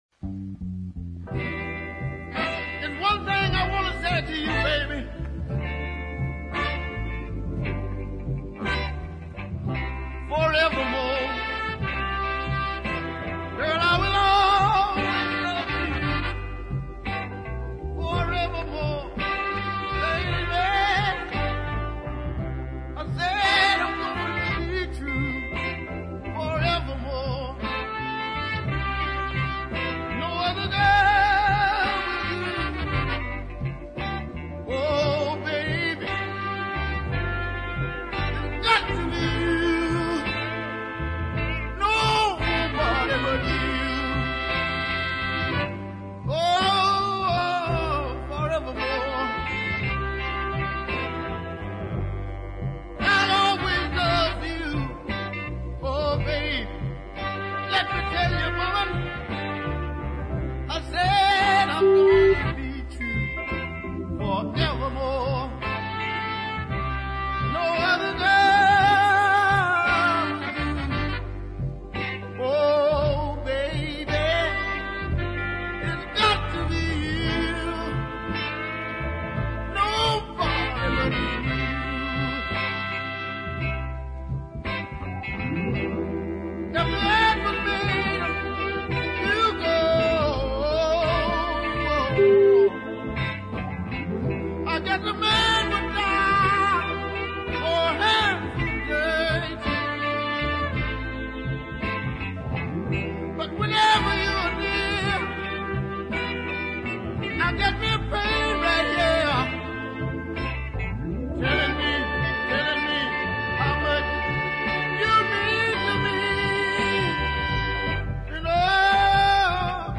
forceful and committed vocal style